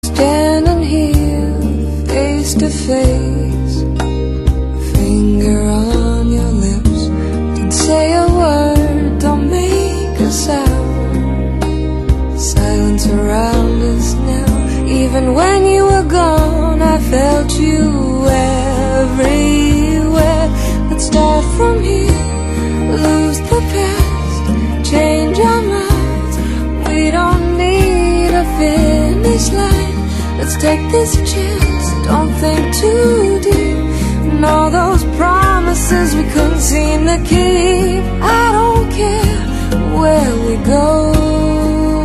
轻快的韩剧插曲